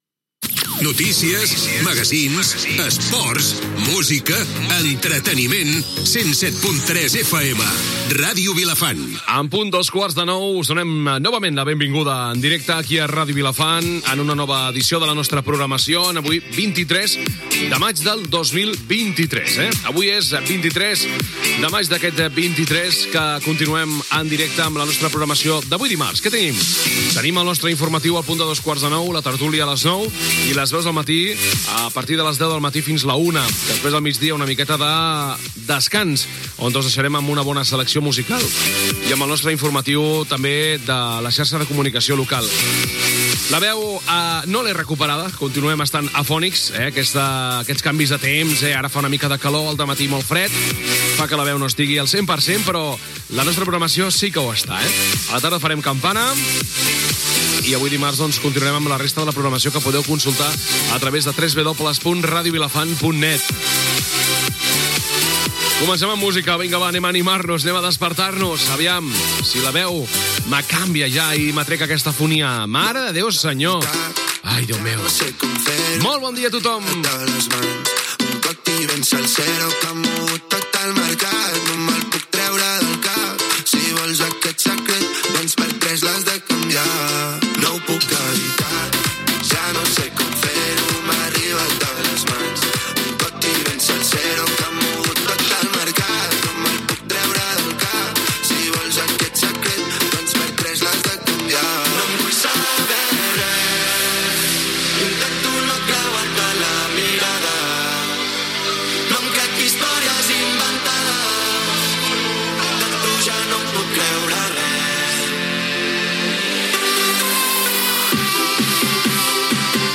Indicatiu de l'emissora, data, sumari de continguts, tema musical, publicitat, tema musical, informatiu comarcal.
Info-entreteniment